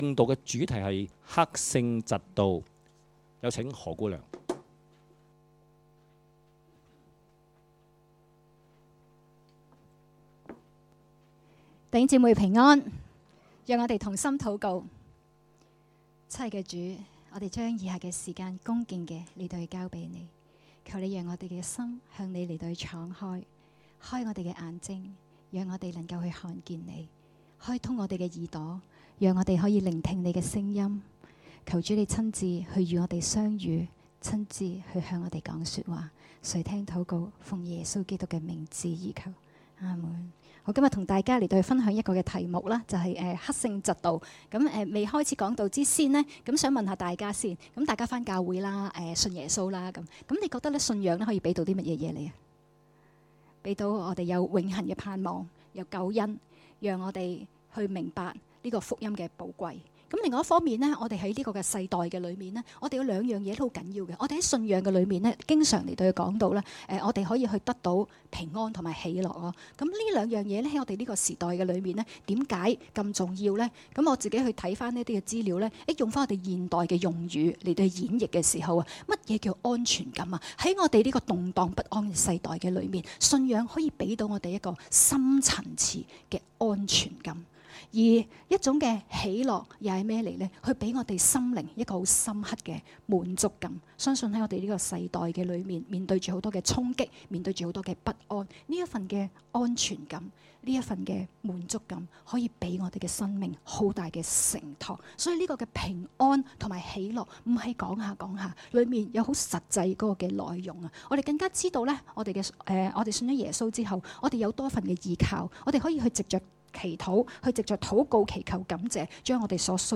講道：克勝嫉妒